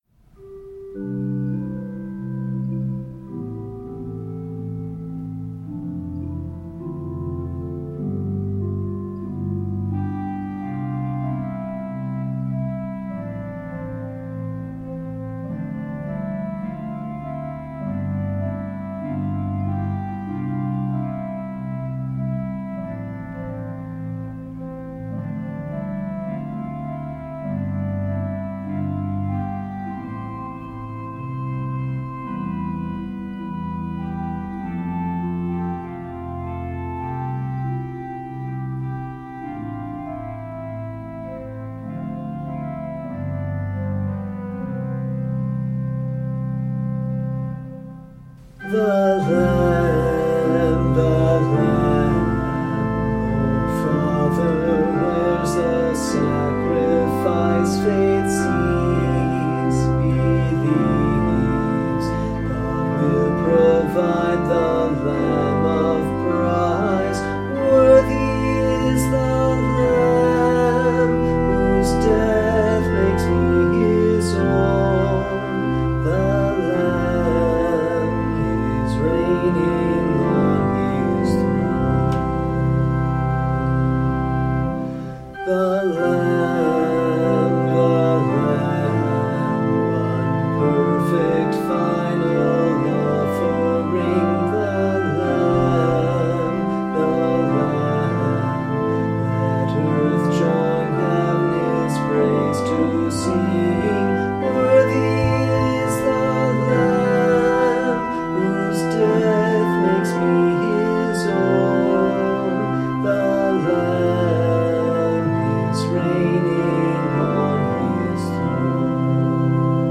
Service: Maundy Thursday
hymn-and-vocals-the-lamb.mp3